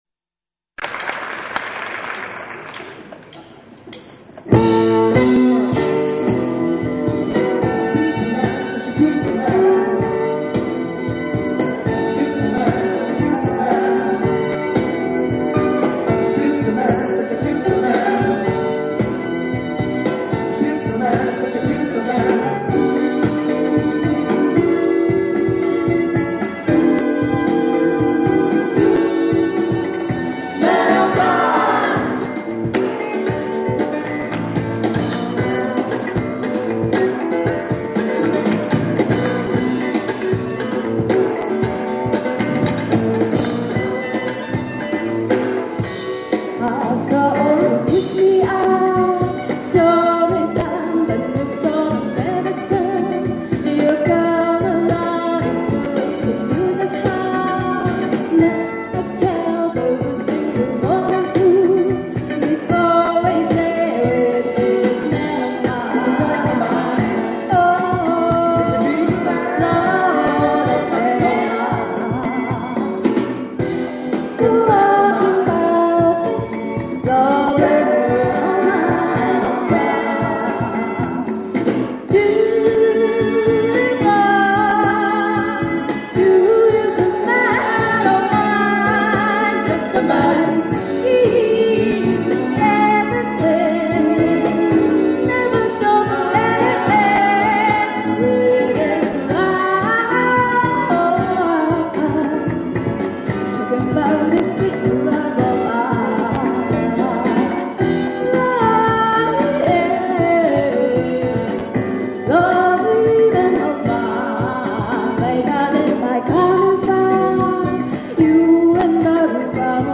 このページでは、過去のライブ音源や映像を、ダウンロード頂けます。